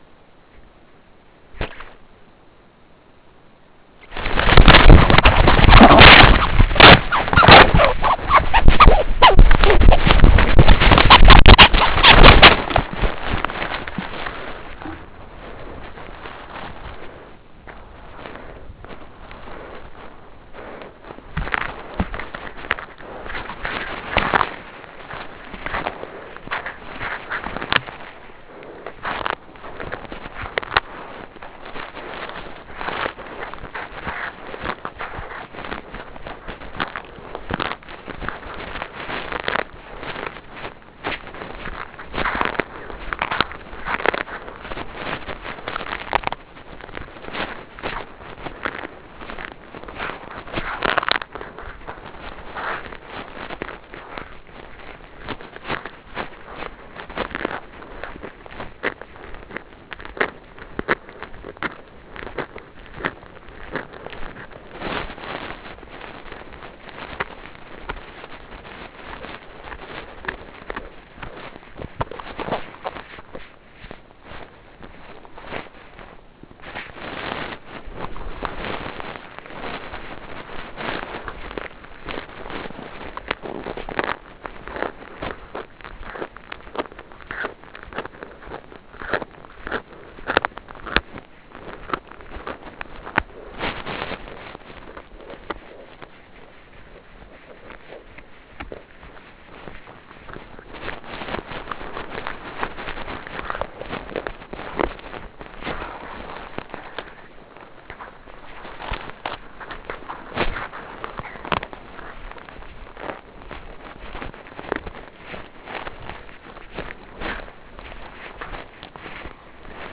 Appendix A6: (Supplementary Materials 6): Squirrel kill (squirrel_kill_feed.wav) | Digital Collections